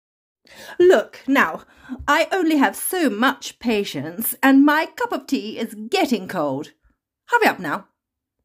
Uk English Lady
45 - 66 ans - Contralto Mezzo-soprano